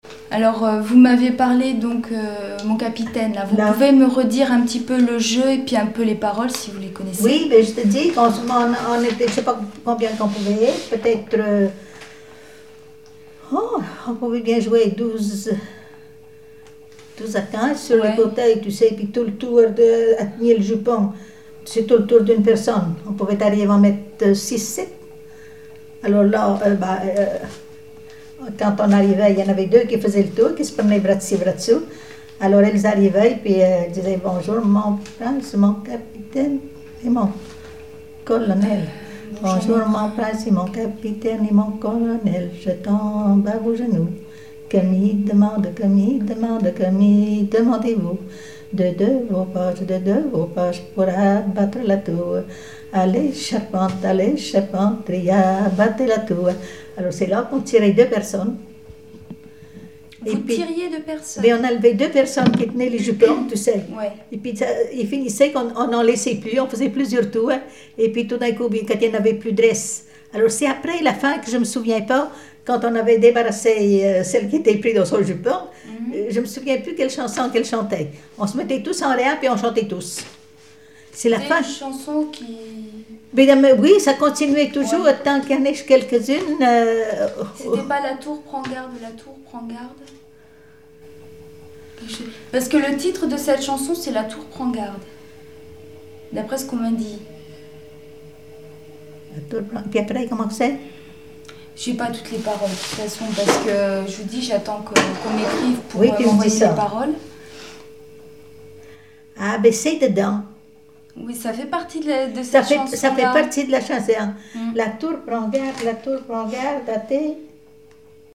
Ronde-jeu La tour prend garde
Témoignages sur les folklore enfantin
Catégorie Témoignage